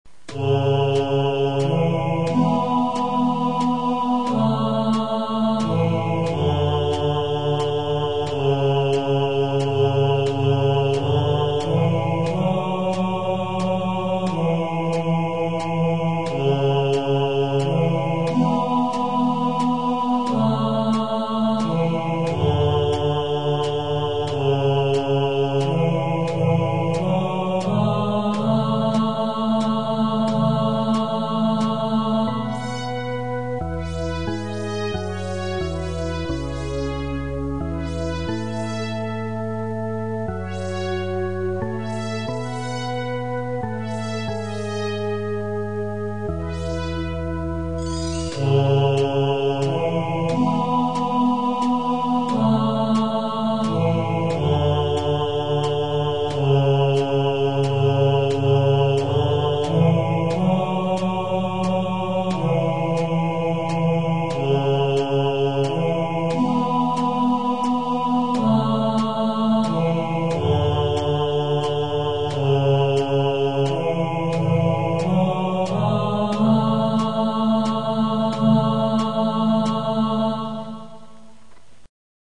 In wav format off the Yamaha keyboard: